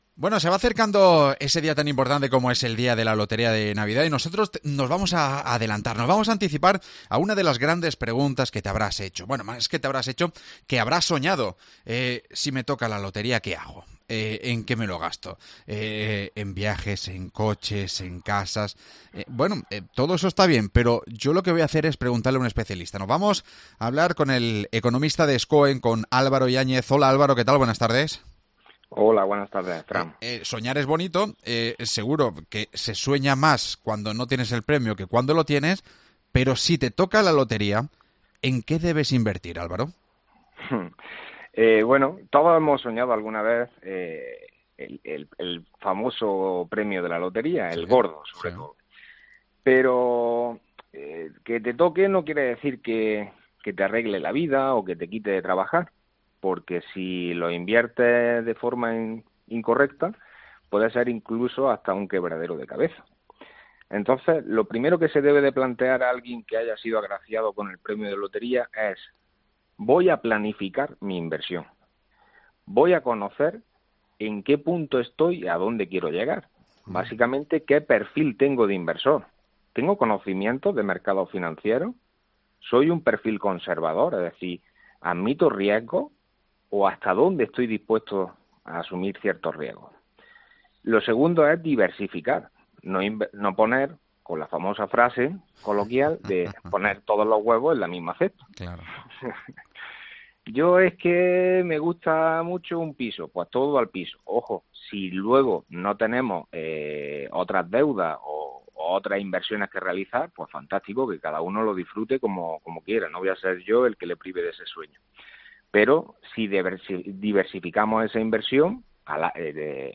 ¿En qué debe invertir uno su premio de la Lotería de Navidad? Hablamos con un gestor